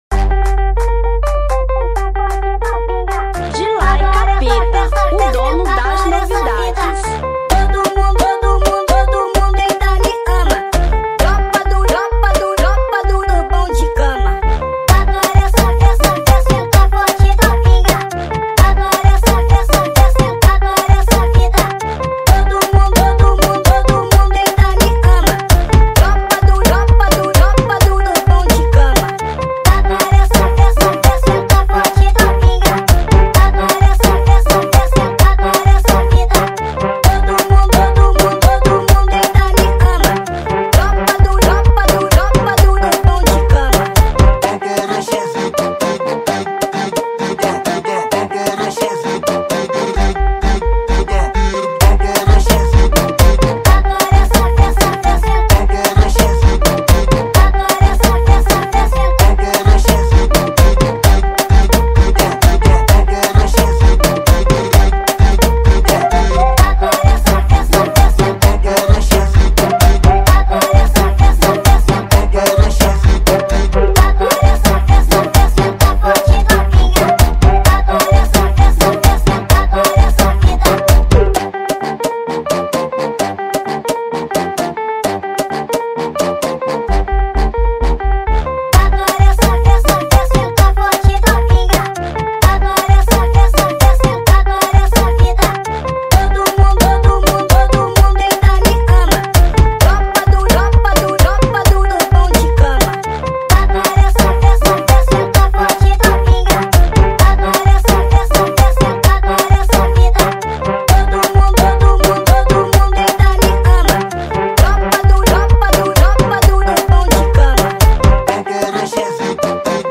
Afro Funk 2025